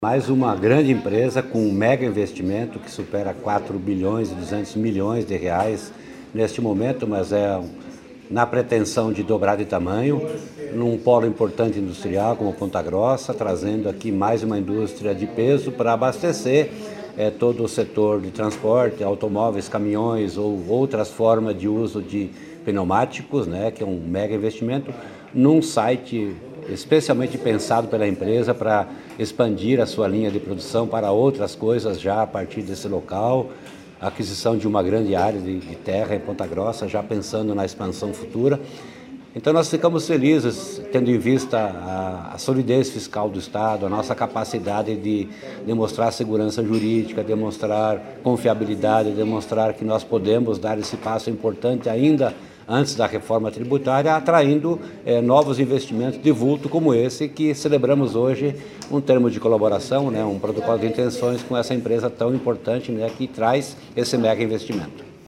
Sonora do secretário da Fazenda, Norberto Ortigara, sobre a instalação da nova fábrica de pneus da XBRI Pneus e da multinacional chinesa Linglong Tire em Ponta Grossa | Governo do Estado do Paraná